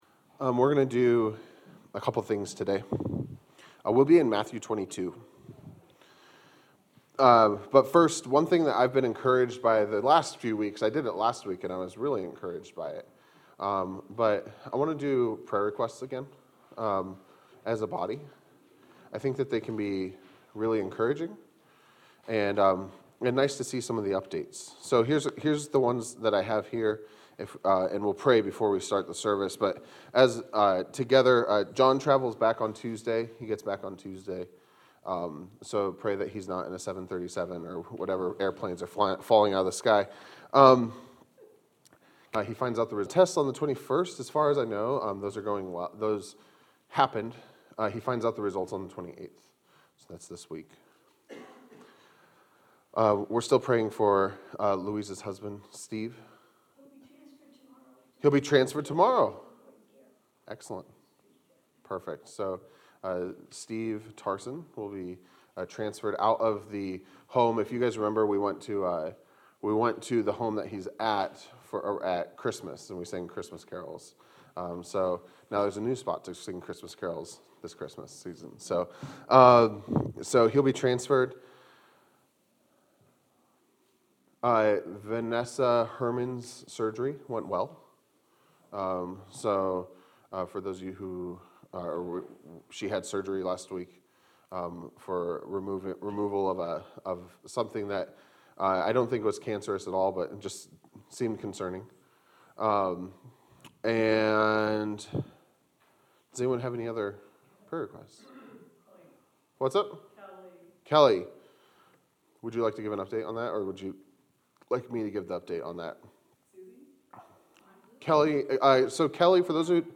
A message from the series "Matthew." Matthew 24:36-51